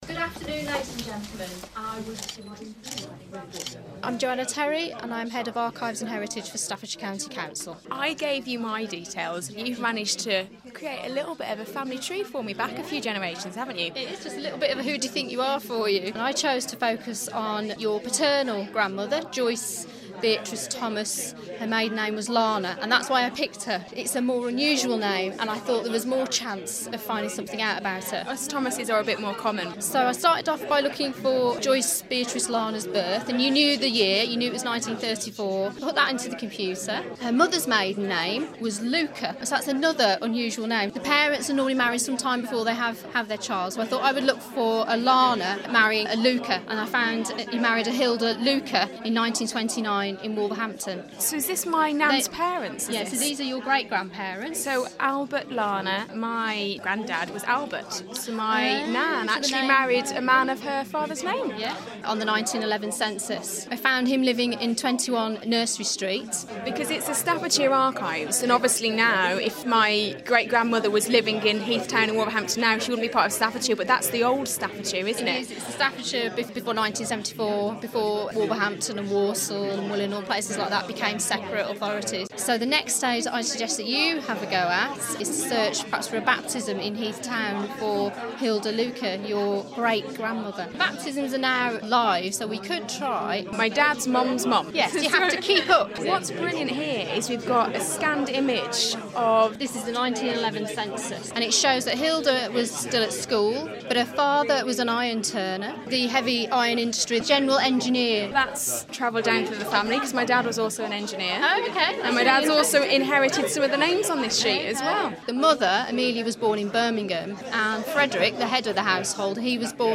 (Broadcast on BBC Stoke & BBC WM, July 2014)